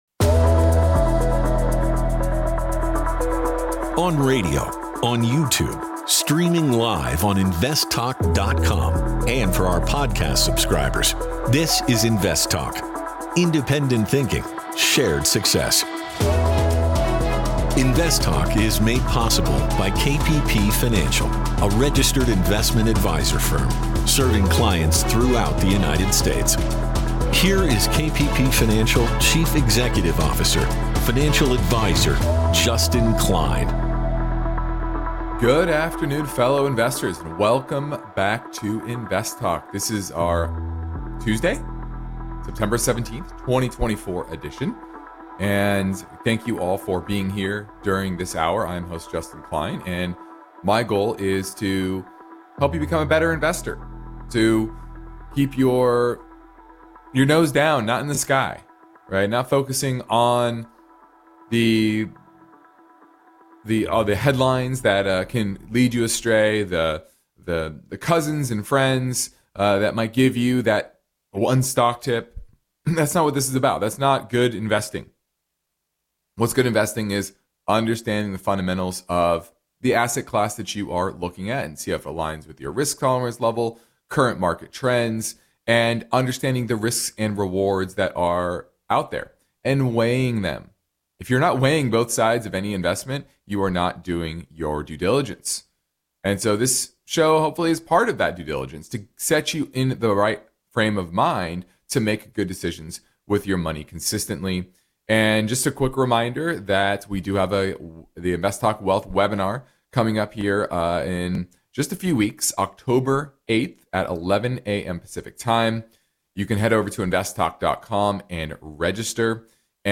a caller question asking about the best way to set-up a college funding mechanism-- for a newborn